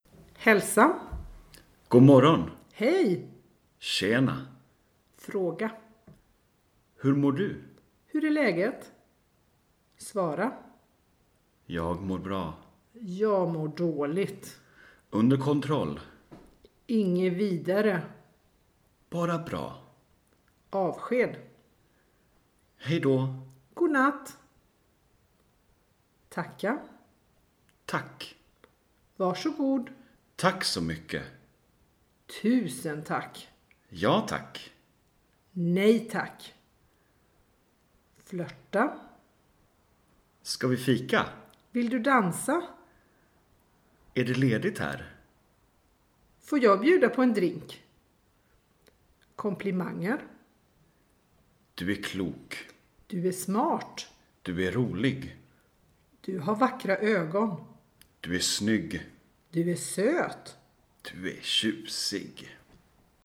Soundfiles Dialog (schwedisch):